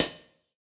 UHH_ElectroHatD_Hit-22.wav